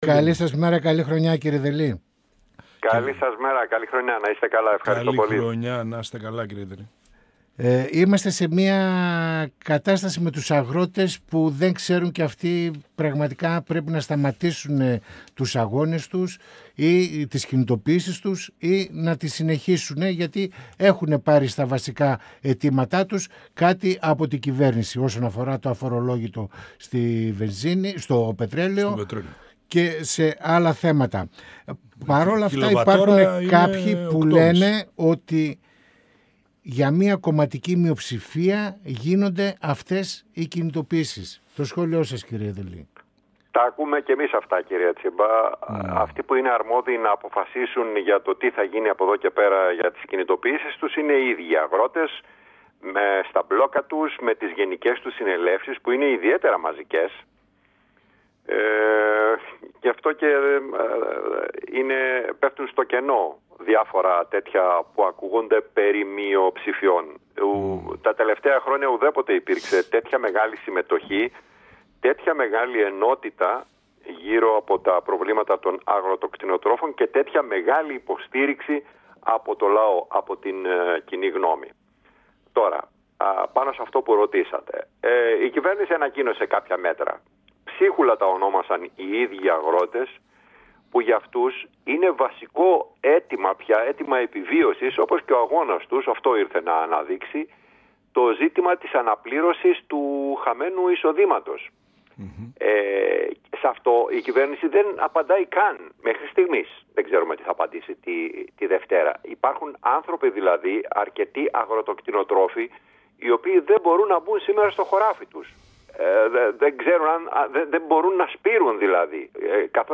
Στα αγροτικά μπλόκα, στο σκάνδαλο του ΟΠΕΚΕΠΕ, αλλά και το πρόβλημα της ακρίβειας σε συνδυασμό με την αγοραστική δύναμη των πολιτών αναφέρθηκε ο  Βουλευτής του ΚΚΕ Γιάννης Δελής, μιλώντας στην εκπομπή «Πανόραμα Επικαιρότητας» του 102FM της ΕΡΤ3.